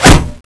Index of /server/sound/weapons/tfa_cso/speargun
metal2.wav